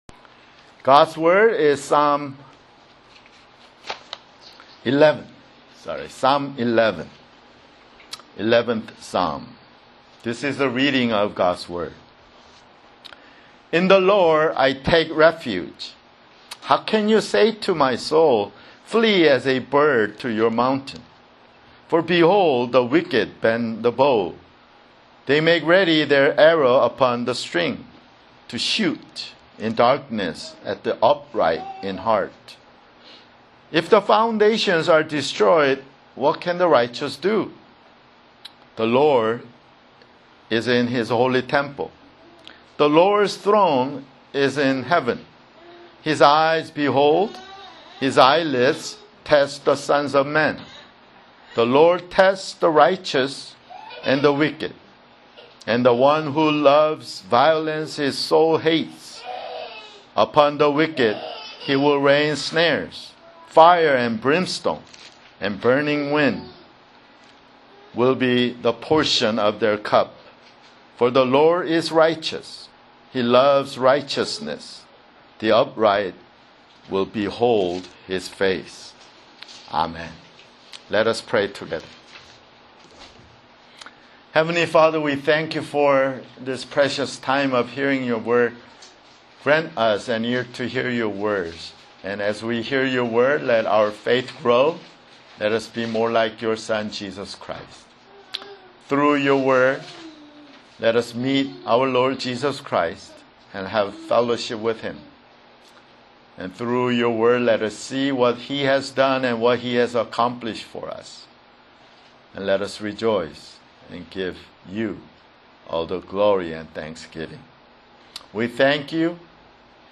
[Sermon] Psalms (11)